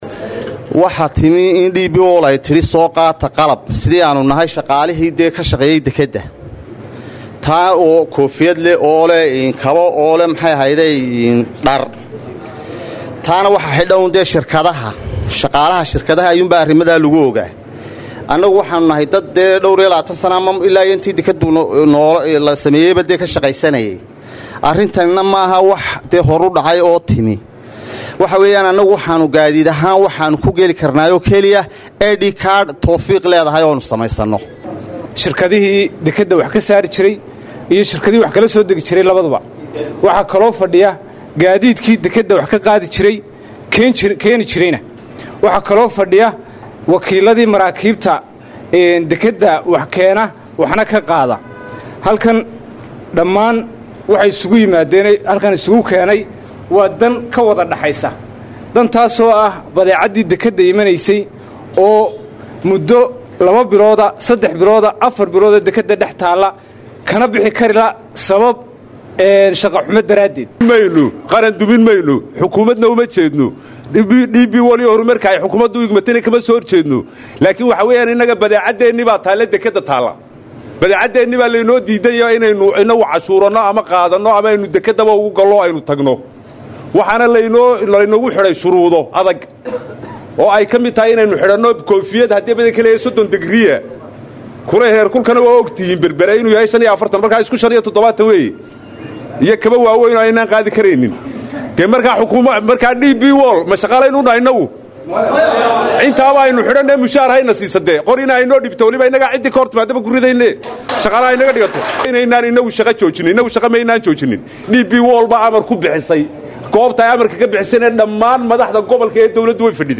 Hoos Ka Dhageyso Codka Ganacsatada